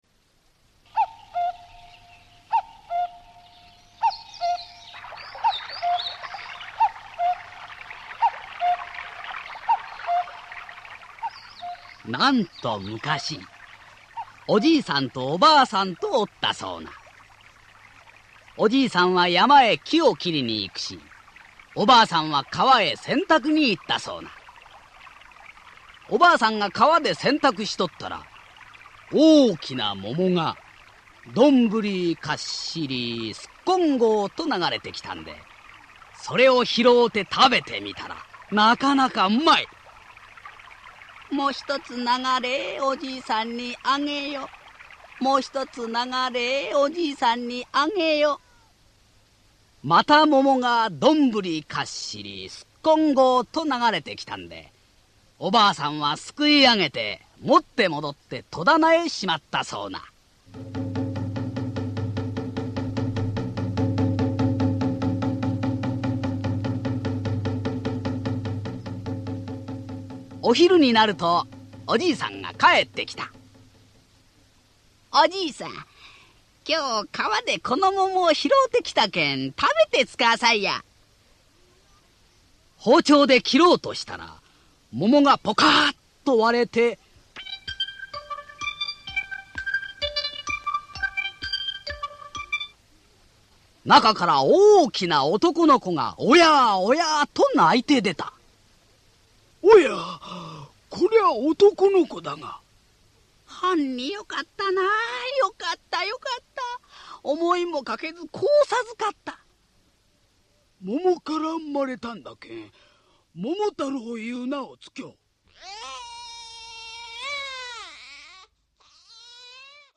[オーディオブック] 桃太郎